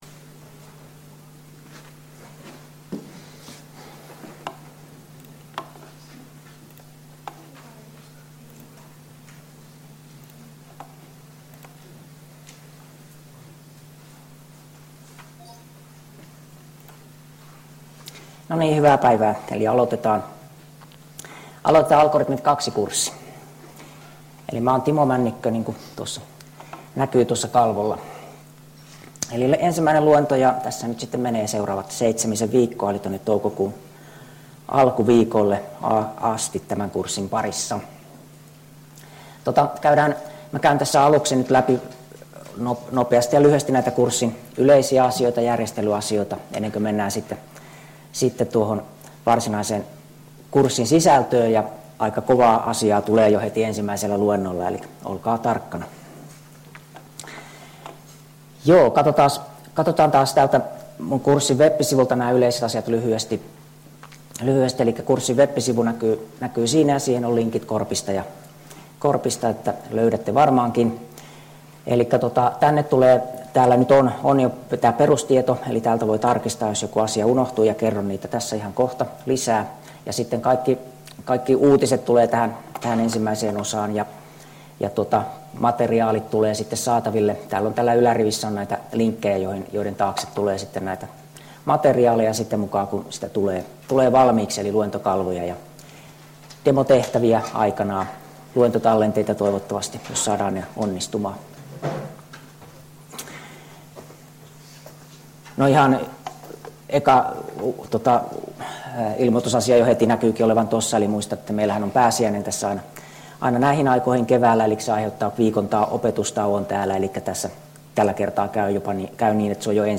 Luento 1 — Moniviestin